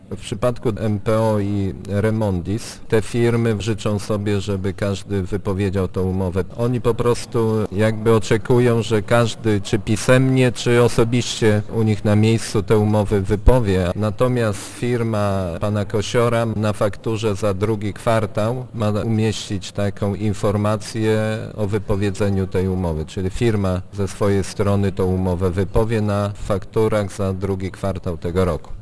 Na terenie gminy Głusk odbiorem odpadów zajmują się obecnie trzy firmy: Miejskie Przedsiębiorstwo Oczyszczania "SITA" z Lublina, "Remondis" ze Świdnika oraz Zakład Transportu Komunalnego Grzegorza Kosiora z Dysa - przypomina wójt Jacek Anasiewicz: